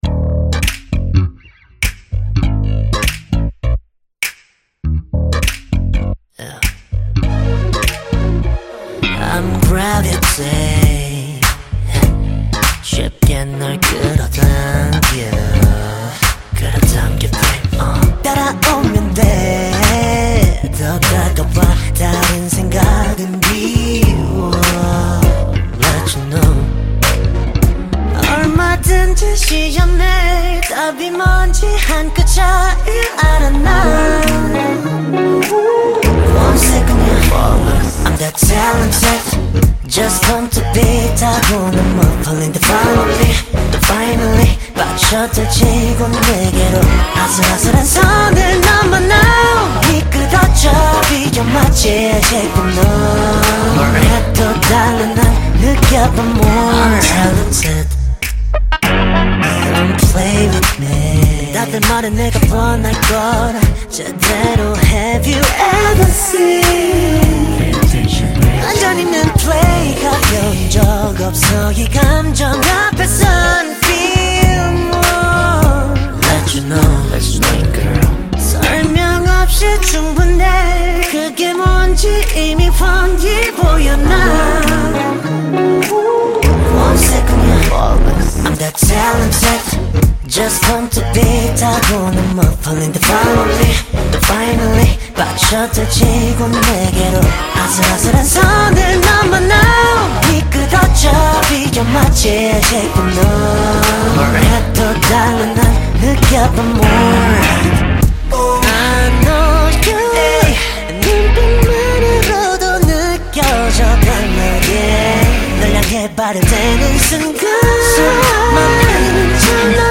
KPop Song
Label Dance